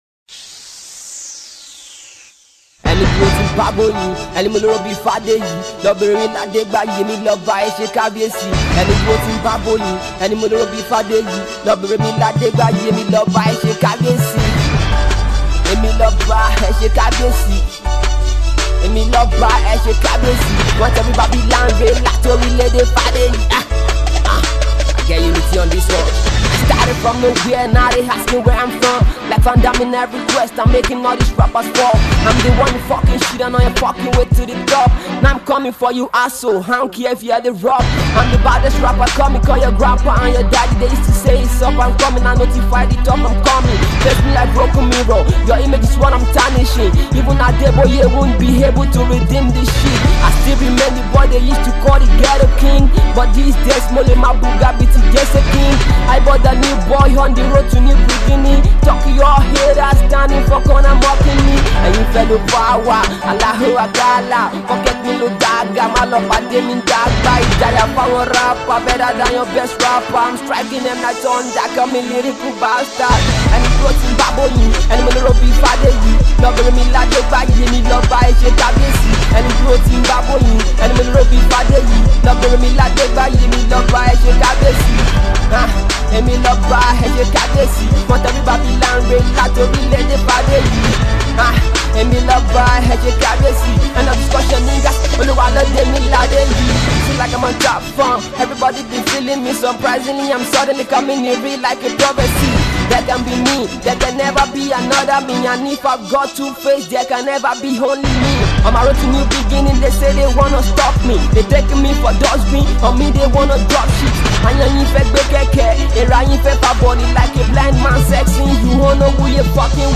[MUSIC]
is a warm-up track for the mix-tape album coming